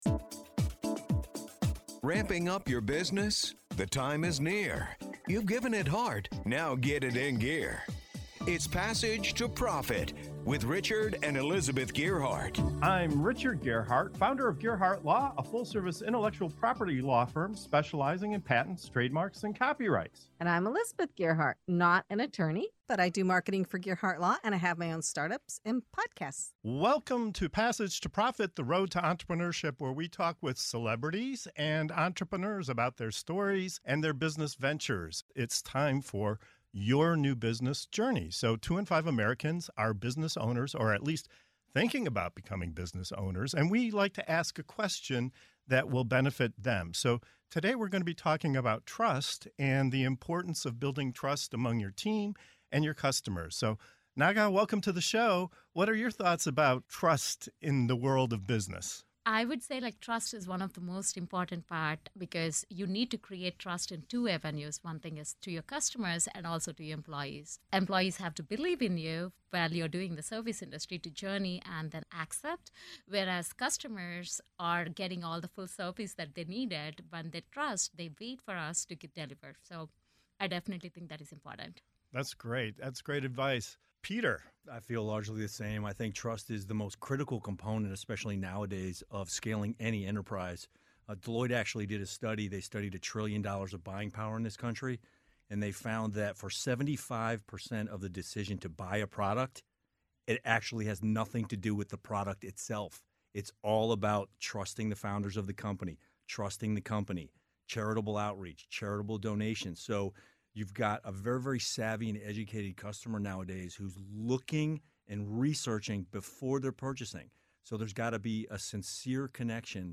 In this segment of “Your New Business Journey” on Passage to Profit Show, our expert guests break down the power of trust in leadership, customer relationships, and team dynamics. From why 75% of buying decisions are based on trust to the art of active listening, we explore practical ways to earn credibility and create lasting connections.